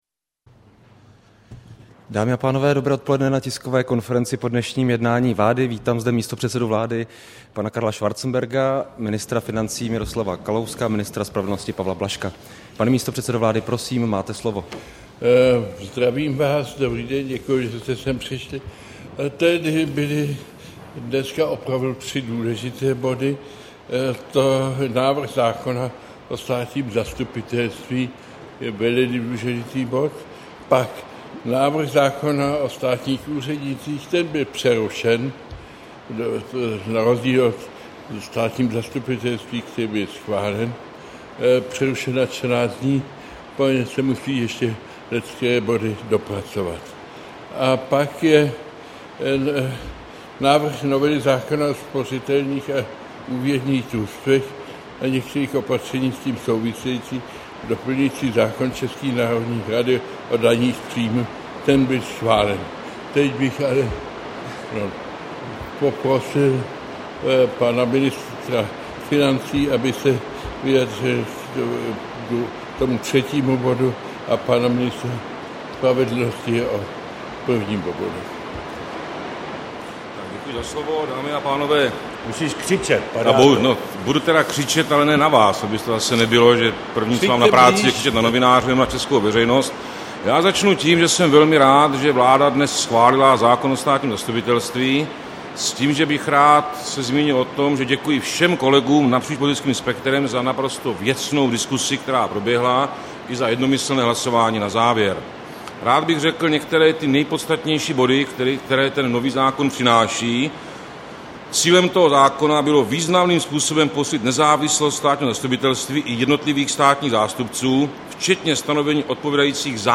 Tisková konference po jednání vlády, 29. května 2013